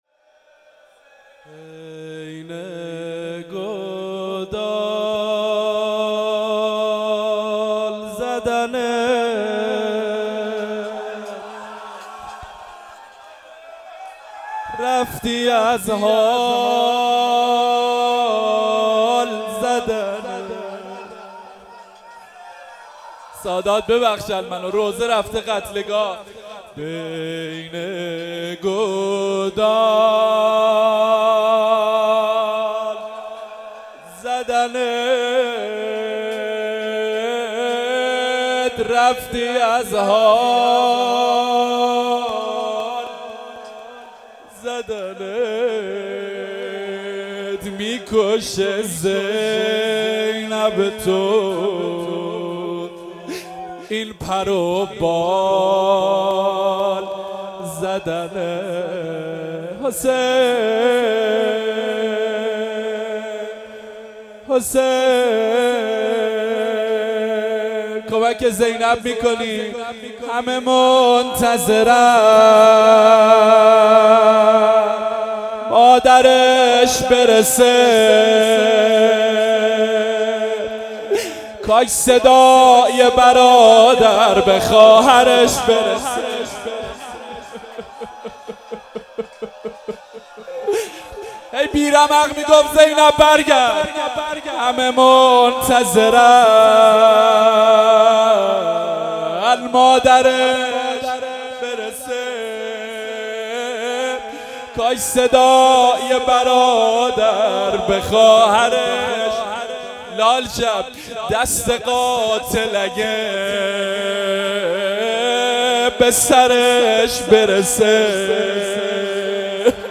مراسم شب ۲۸ محرم ۱۳۹۷
دانلود روضه